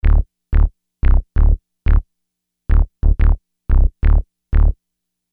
Hiphop music bass loops 4
Hiphop music bass loop - 90bpm 62